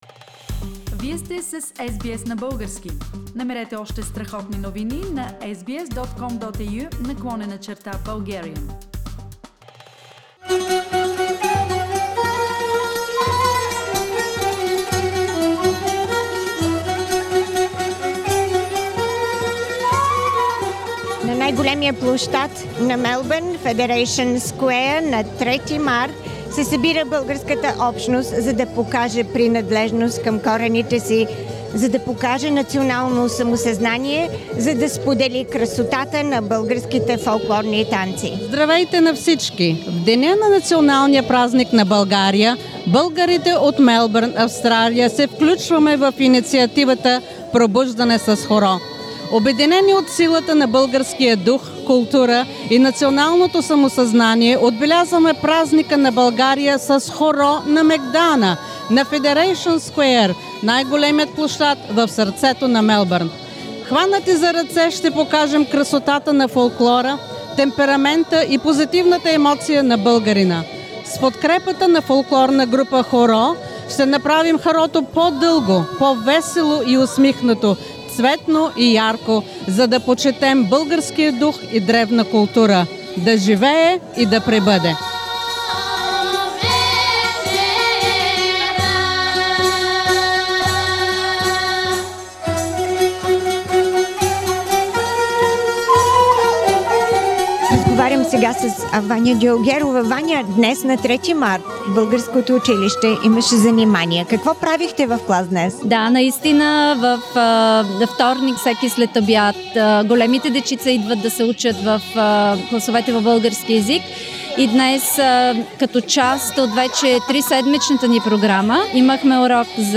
Meet the dancers, the singers, the audience